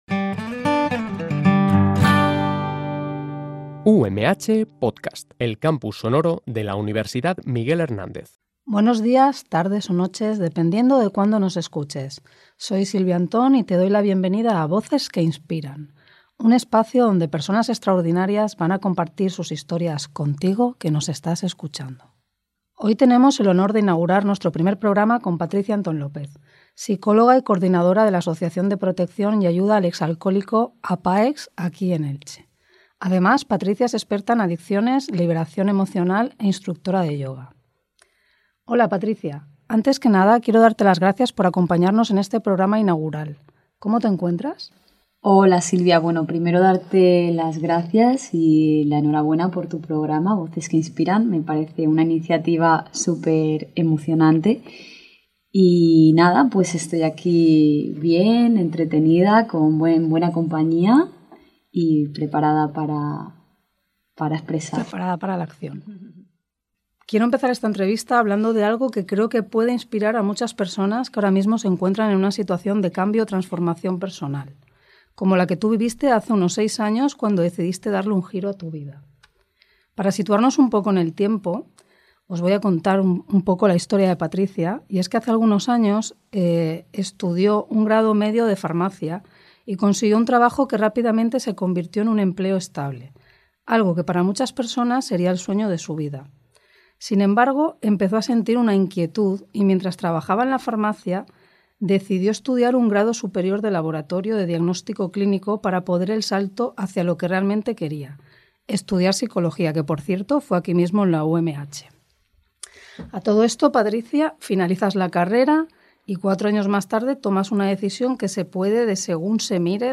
Voces que Inspiran es un programa de entrevistas diseñado para destacar las historias, experiencias y reflexiones de personas que dejan una huella positiva en el mundo, con el objetivo de motivar y enriquecer a nuestra audiencia.